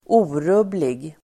Uttal: [²'o:rub:lig]